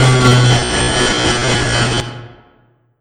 jumpscare.wav